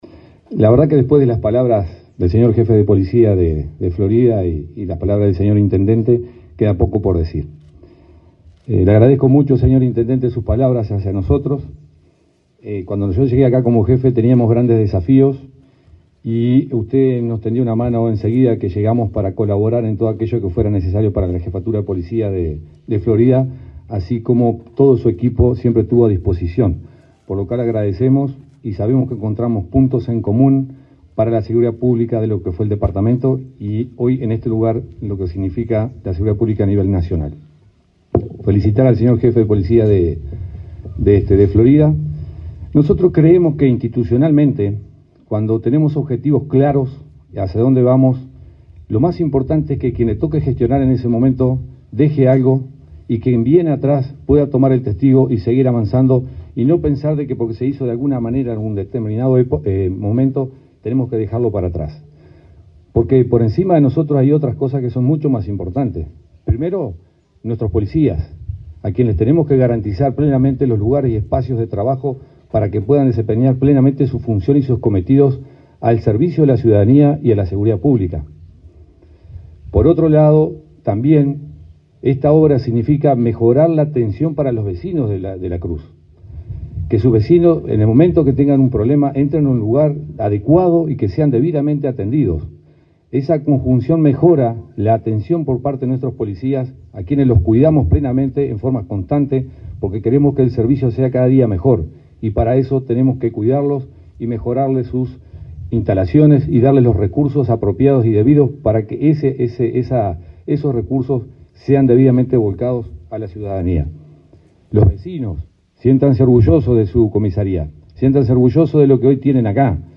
Palabras del director de la Policía Nacional, José Azambuya
El director de la Policía Nacional, José Azambuya, se expresó durante el acto de inauguración de la seccional 8.ª de la localidad de La Cruz, en el